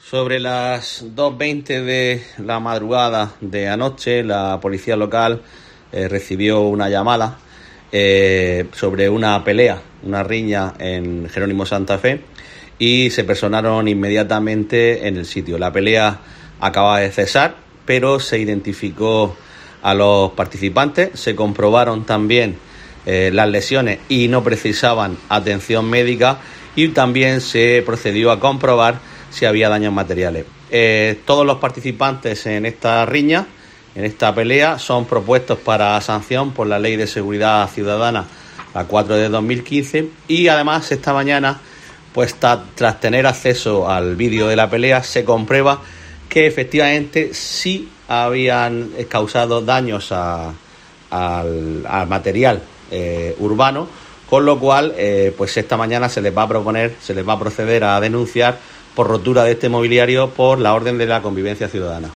Juan Miguel Bayonas, edil Seguridad Ciudadana de Ayuntamiento de Lorca